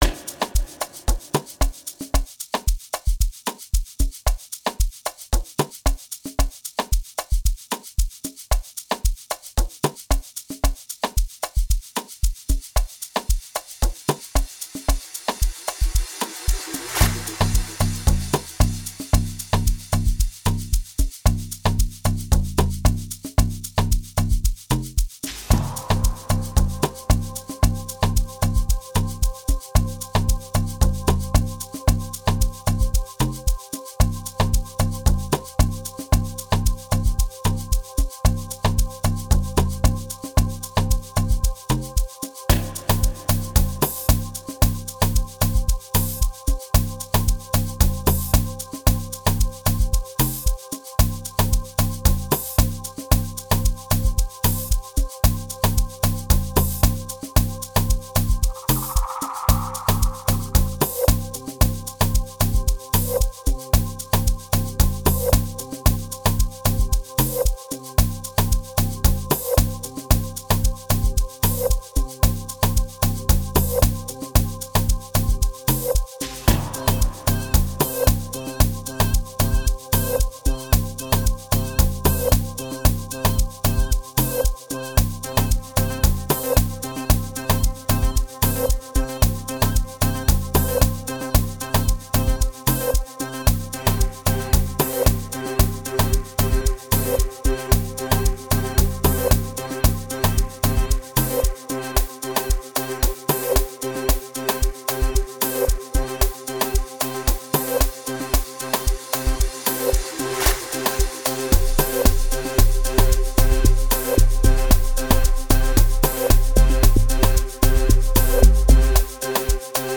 06:58 Genre : Amapiano Size